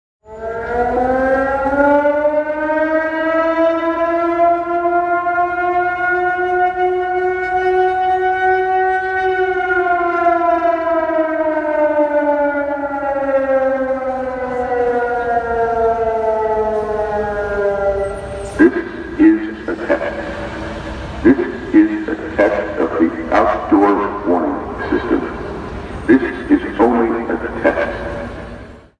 空袭警报器 (测试)
描述：每周二中午，旧金山都会测试他们的户外警报警报15秒。我想这是在发生地震，海啸或空袭的情况下。 用iPhone 7s +录制（不幸的是），因为我的Tascam电池在记录之前已经死了。